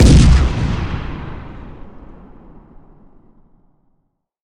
Tank fire Mixed
battle boom cannon crack explosion explosive gun m1a1 sound effect free sound royalty free Nature